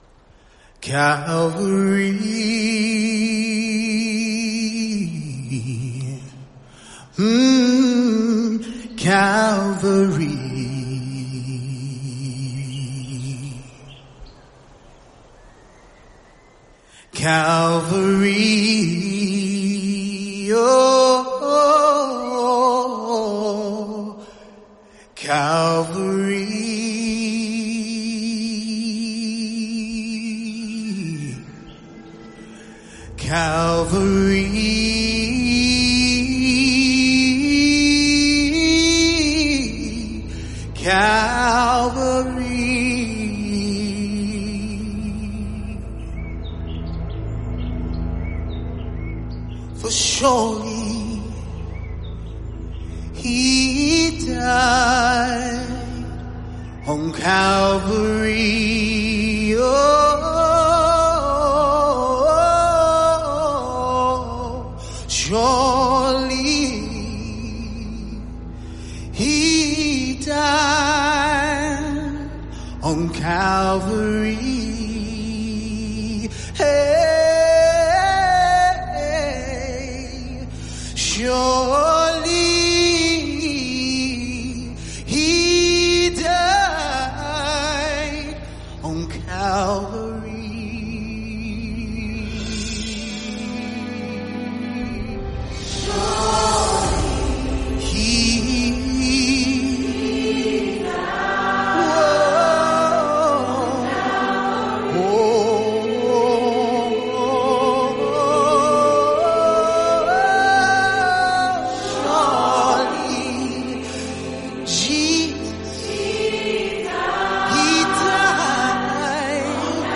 Traditional African-American spiritual
vocalist